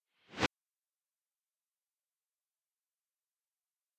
slide_recover.ogg